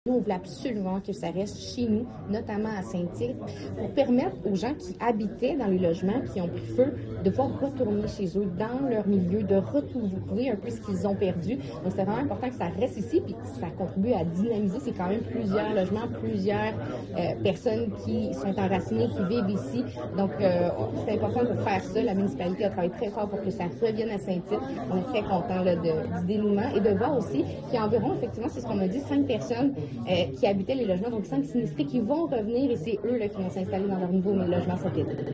La députée de Charlevoix–Côte-de-Beaupré, Kariane Bourassa.
La députée de Charlevoix–Côte-de-Beaupré, Kariane Bourassa lors du point de presse concernant l'annonce à l'Hôtel de ville de Saint-Tite-des-Caps.